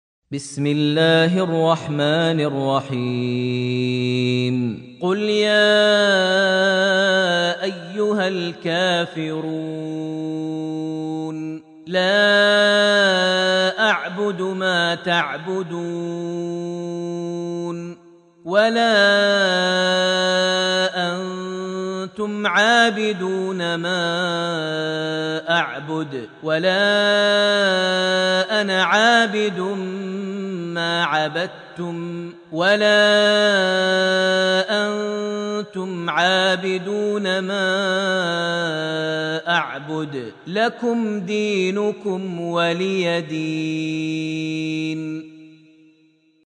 surat Al-Kafirun > Almushaf > Mushaf - Maher Almuaiqly Recitations